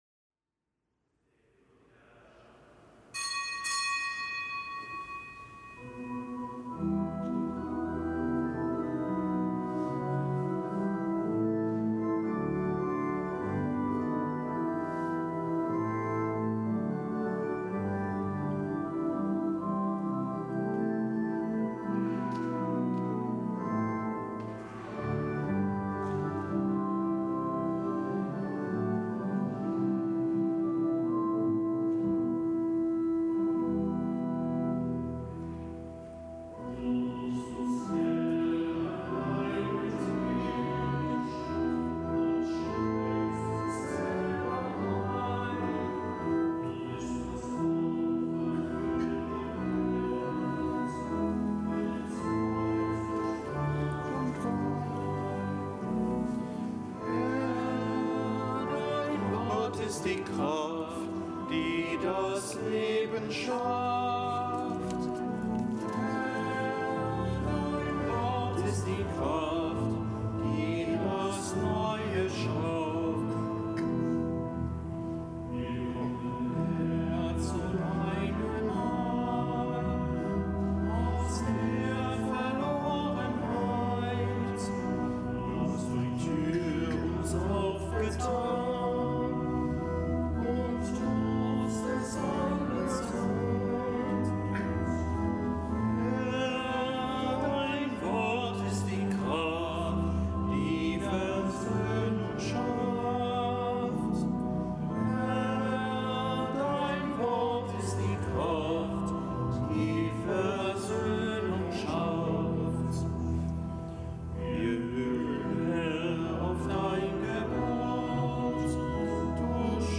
Kapitelsmesse aus dem Kölner Dom am Dienstag der sechsten Woche im Jahreskreis.